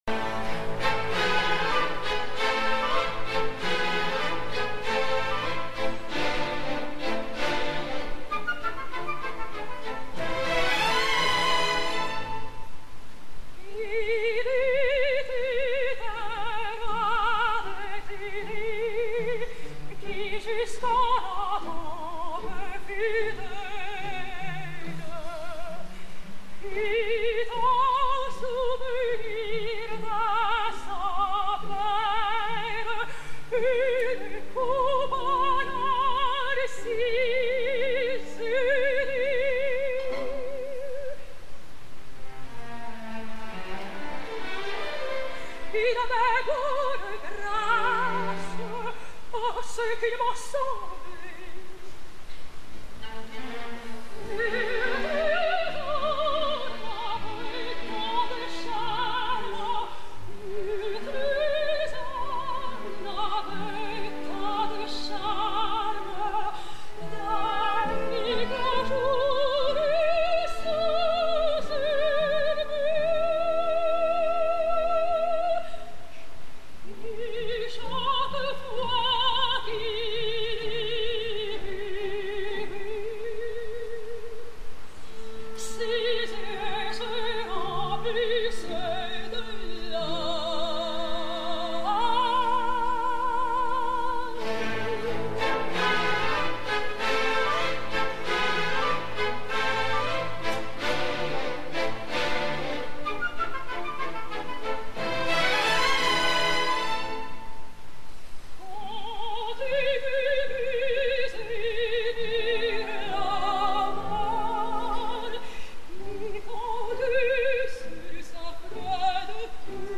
Marguerite: Angela Gheorghiu/Solie Isokoski
Tots els fragments son del dia de l’estrena, 11 d’octubre de 2008.
L’esplendorosa Angela Gheorghiu a “Il était un roi de Thulé…Ah! je ris de me voir”, és a dir fent la Castafiore. La crítica ha destacat la gran forma vocal però li ha retret la seva actuació escènica, carregada de gestualitat de gran diva.